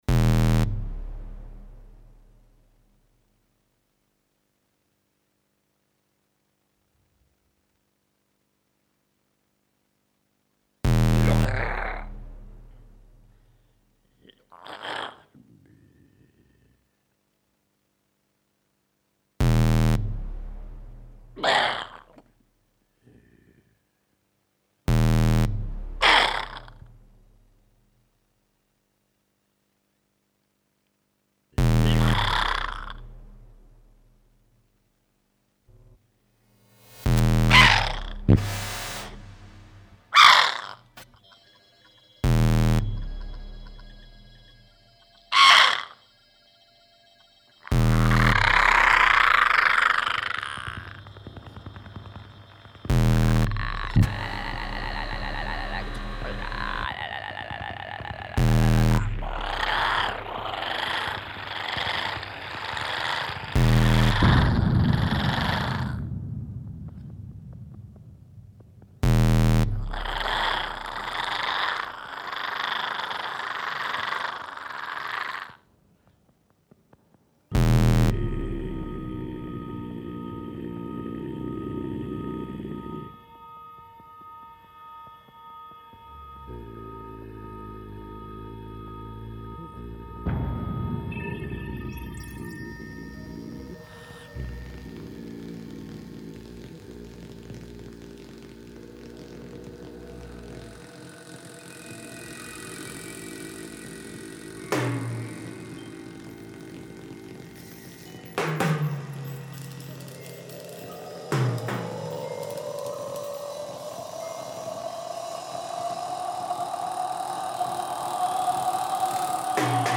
Recorded at Le M !lieu in January 2022